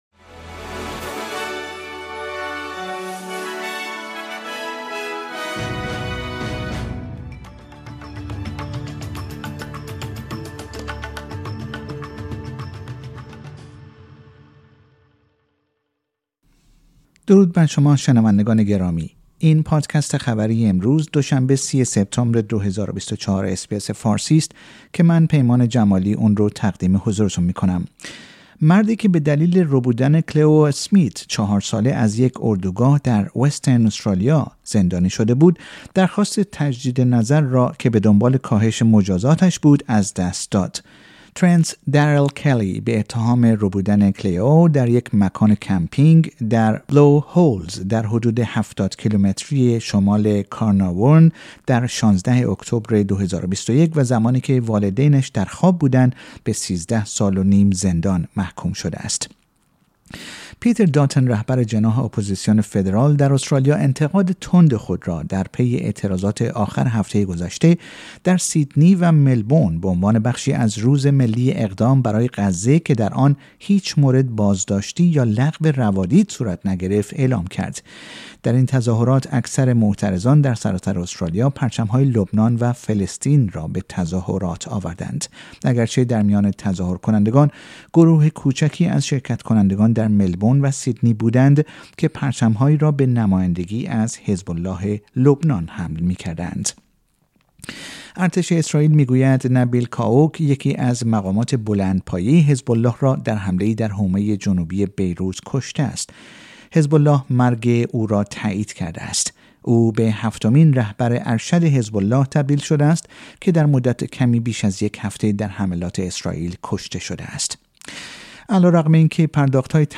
در این پادکست خبری مهمترین اخبار استرالیا در روز دوشنبه ۳۰ سپتامبر ۲۰۲۴ ارائه شده است.